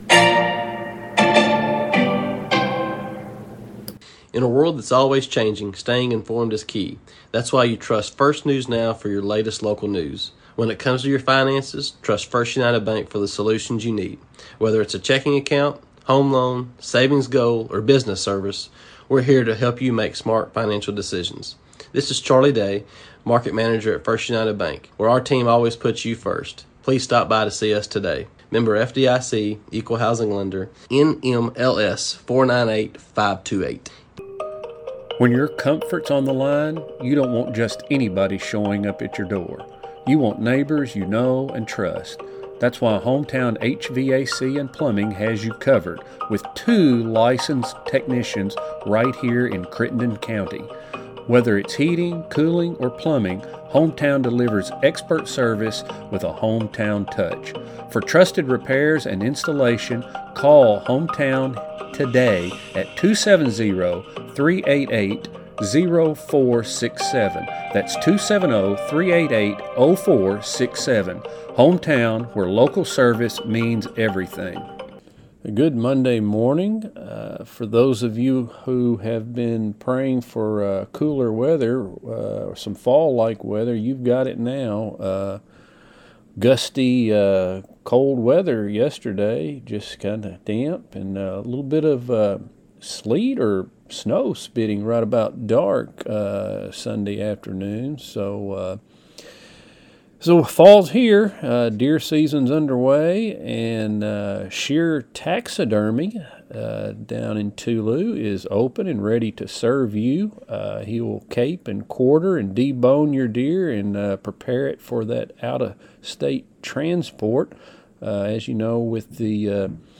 News | Sports | Interview